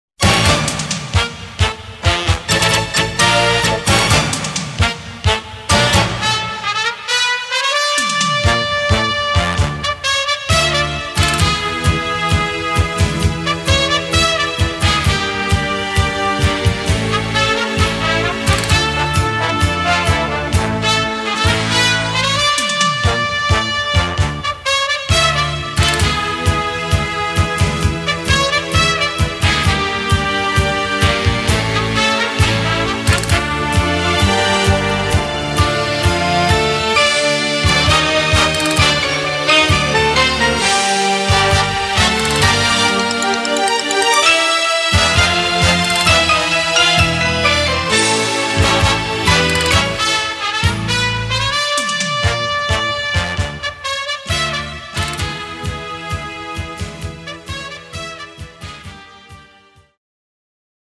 Gattung: Moderne Blasmusik
A4 Besetzung: Blasorchester Zu hören auf